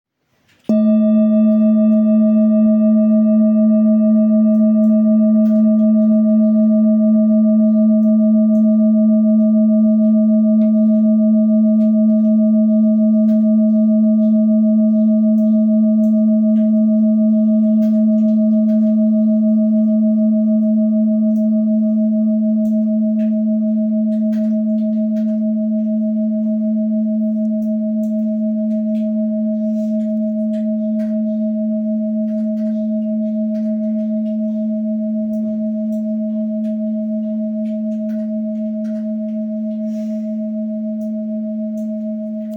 Singing Bowl, Buddhist Hand Beaten, Plain, Antique Finishing, Select Accessories
Material Seven Bronze Metal